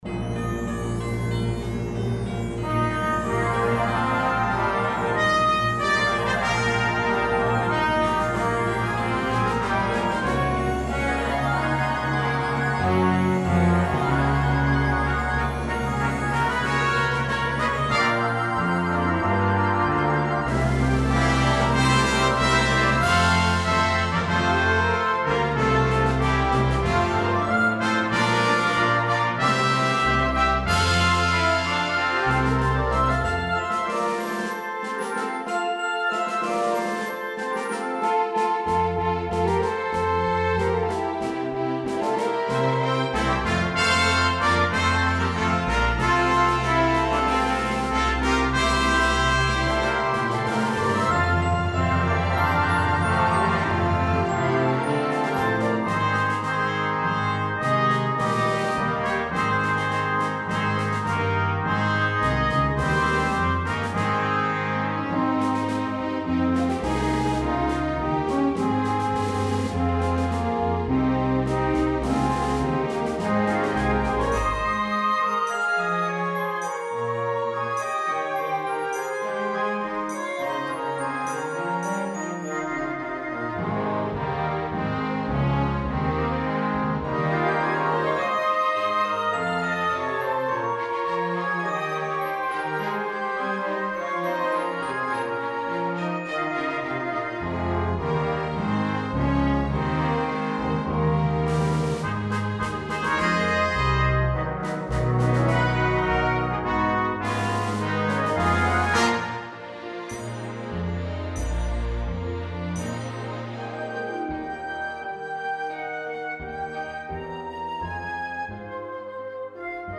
A joyful and festive overture for a service or a program.